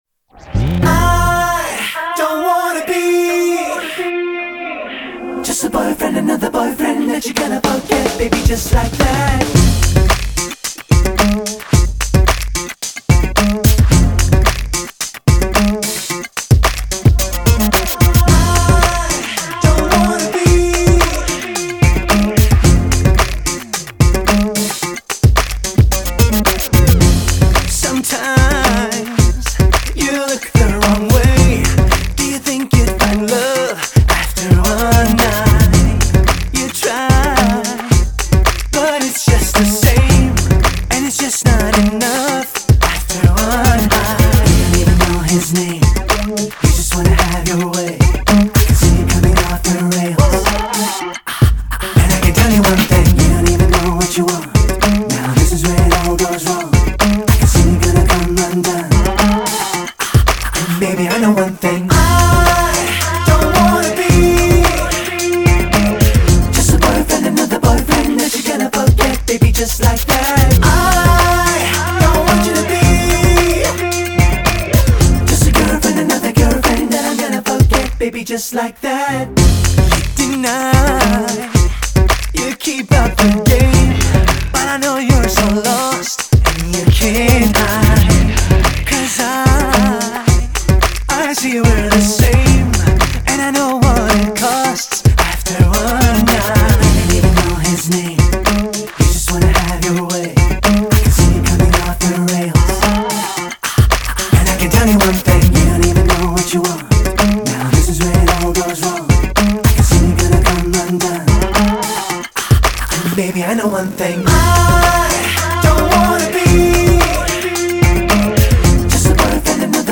всемирно известный турецкий исполнитель поп-музыки.